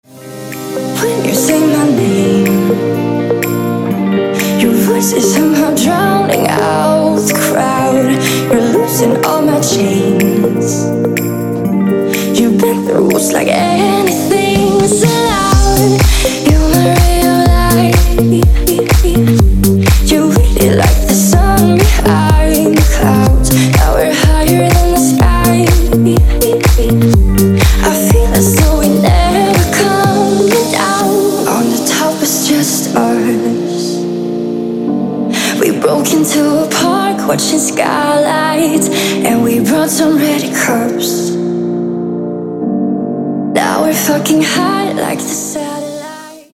• Качество: 160, Stereo
поп
женский вокал
dance
vocal